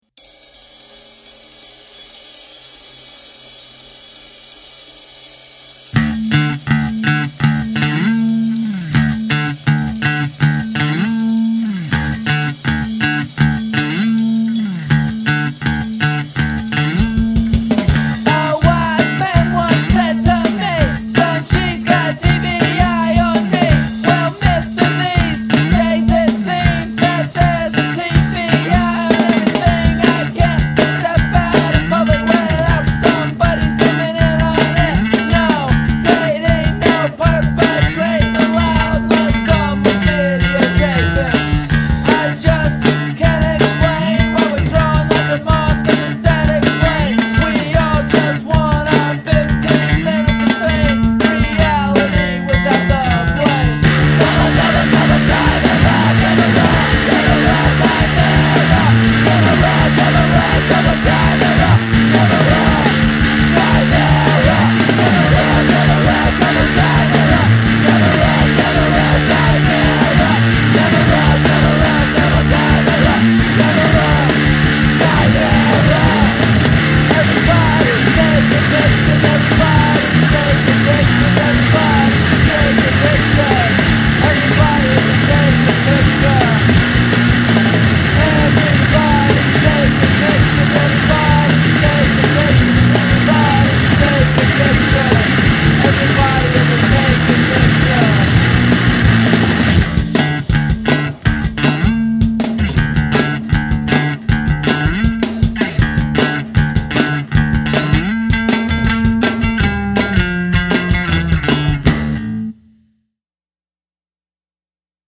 crisp snare attack, fuzzed-out bass lines